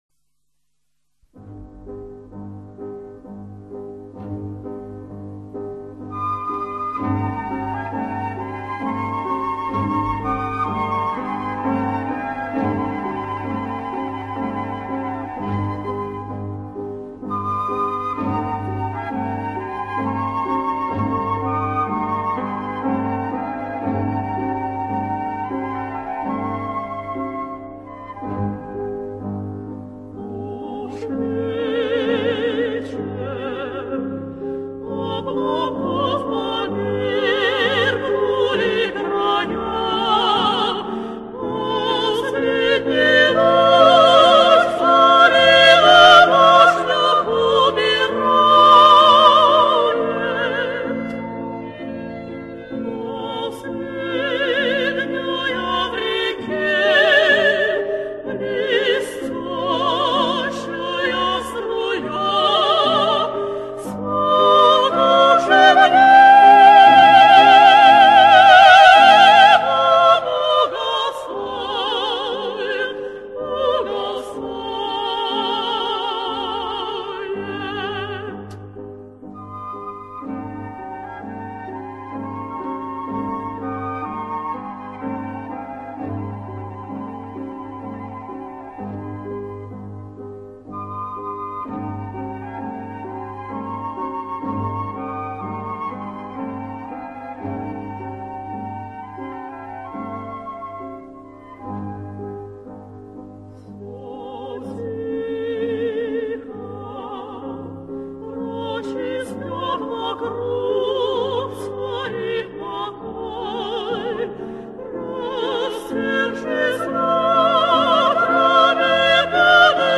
b) Duo
(♫) Soprano et mezzo soprano :
0107_Duo_La_dame_de_pique_Piotr_Ilitch_Tchaikovsky_Soprano_et_mezzo.mp3